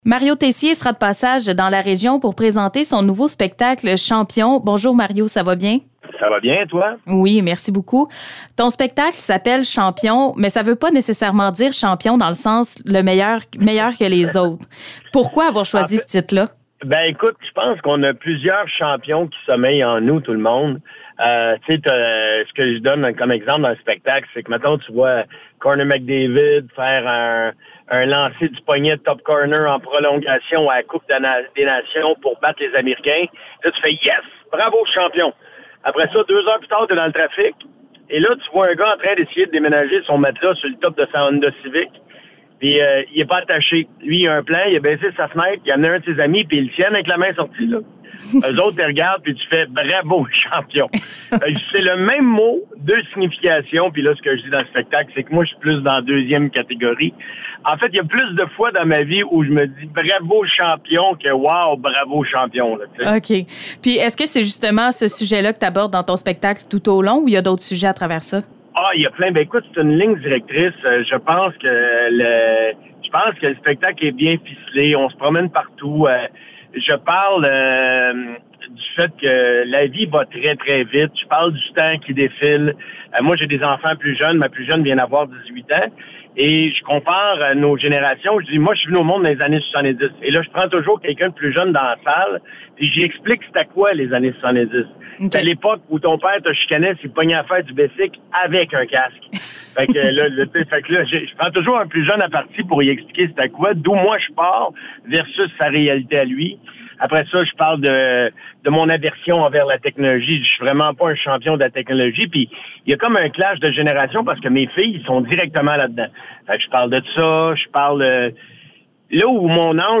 Entrevue avec Mario Tessier concernant son spectacle à Victoriaville le 9 mai prochain.